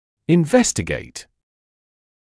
[ɪn’vestɪgeɪt][ин’вэстигейт]изучать, исследовать; собирать сведения